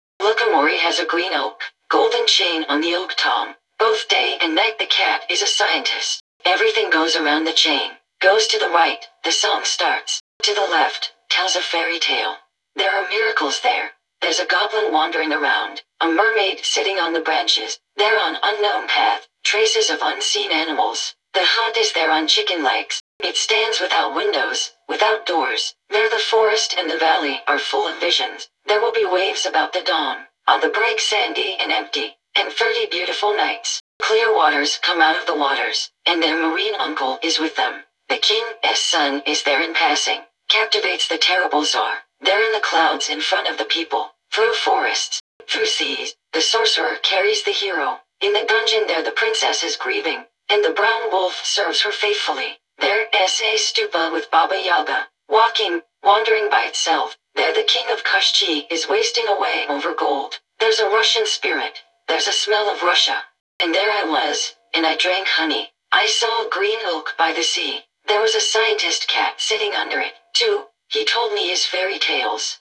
Fallout_4 / English /F4_F_DLC01RobotCompanionFemaleDefault_Eng /OLD /G_57000 (Eng).wav